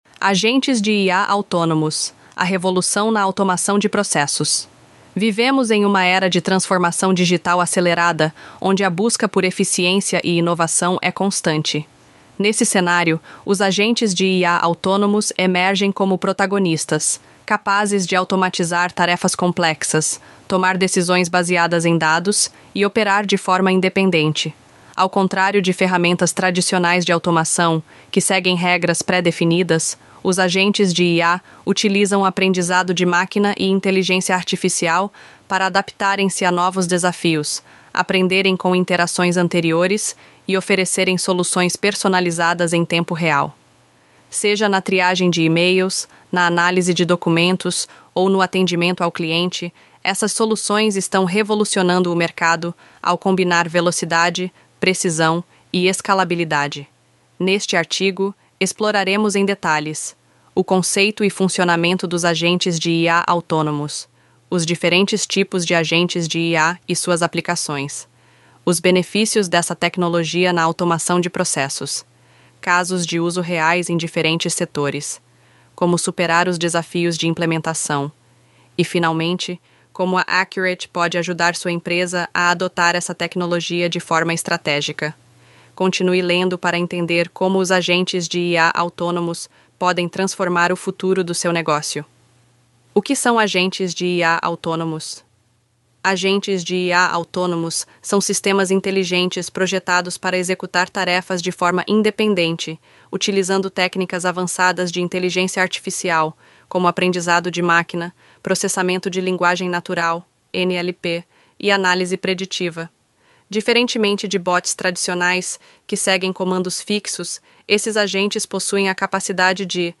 Texto-sobre-Agentes-de-IA-Autonomos-Narracao-Avatar-Rachel-ElevenLabs.mp3